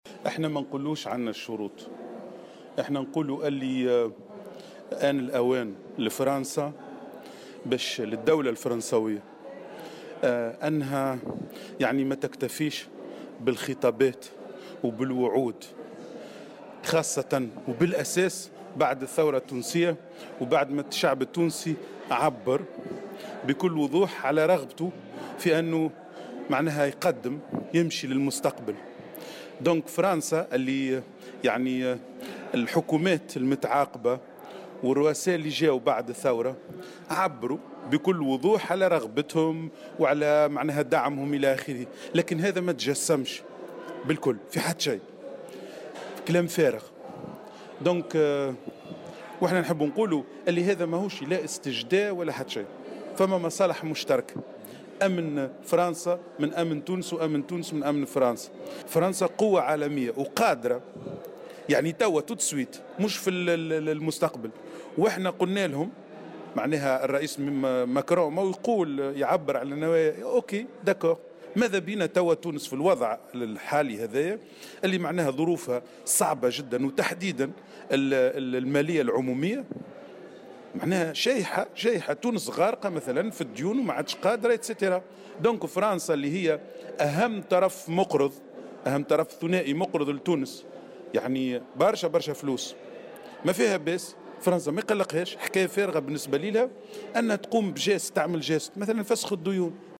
وقال النائب عن كتلة الجبهة الشعبية، فتحي الشامخي في تصريح لمراسل "الجوهرة أف أم" قبل انعقاد الجلسة العامة المنعقدة اليوم الخميس بحضور الرئيس الفرنسي إنه لا يجب الاكتفاء بالوعود والخطابات بل تجسيد ذلك على أرض الواقع.